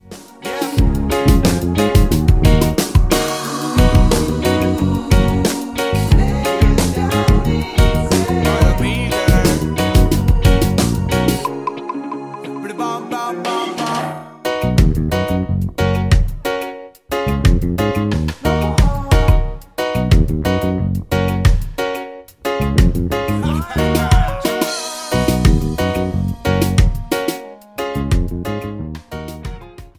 Gb
Backing track Karaoke
Pop, 2010s